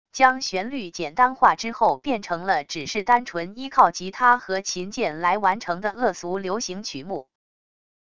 将旋律简单化之后变成了只是单纯依靠吉他和琴键来完成的恶俗流行曲目wav音频